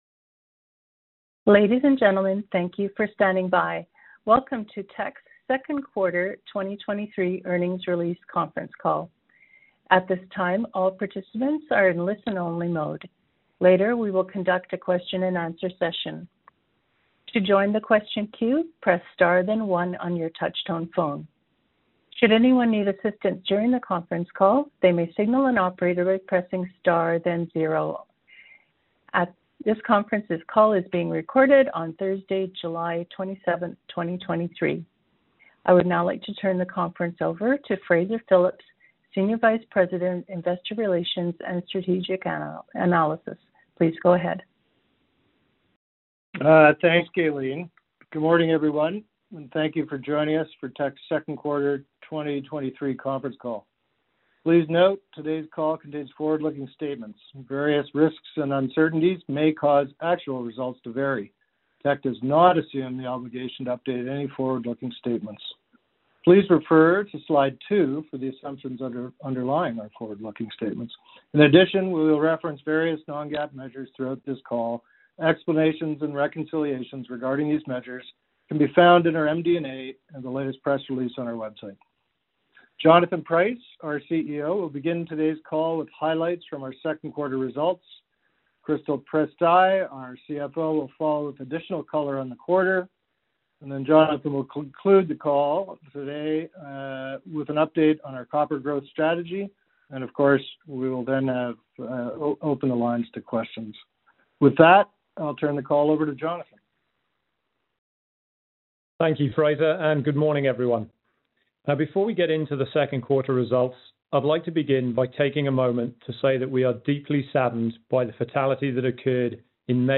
Q2 2023 Conference Call
A webcast to review the results will be held as follows: